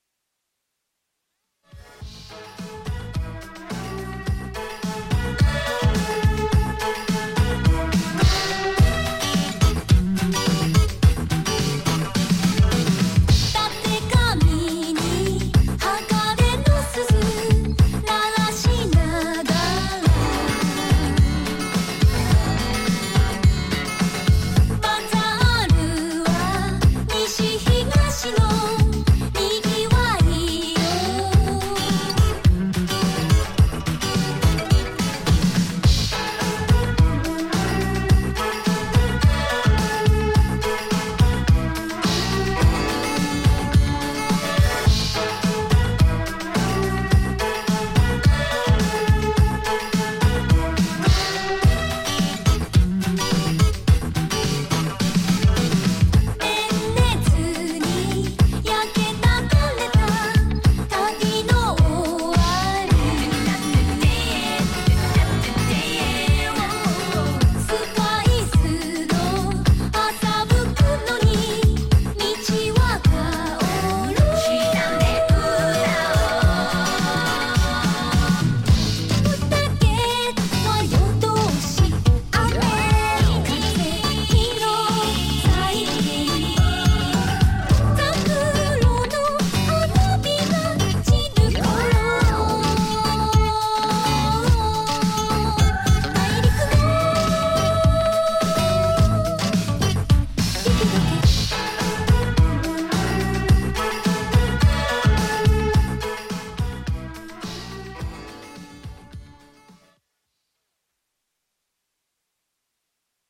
ジャンル(スタイル) NU DISCO / DISCO / RE-EDIT